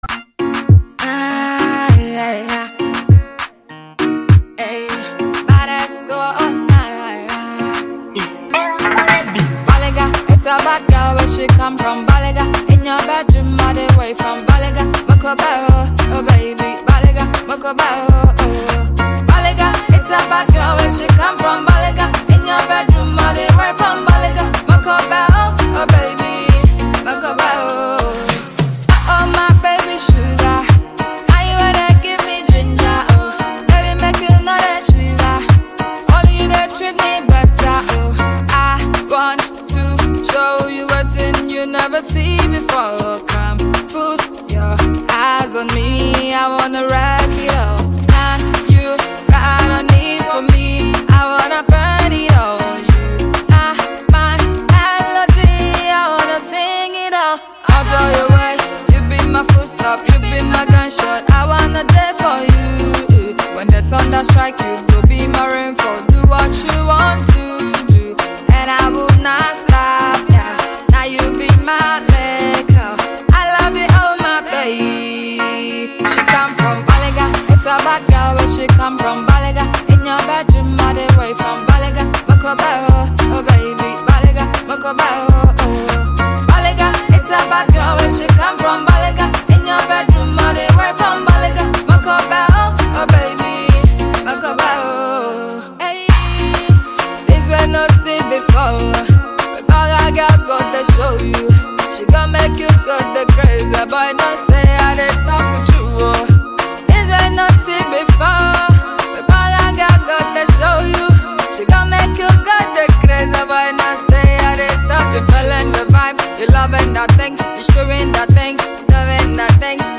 Ghanaian songstress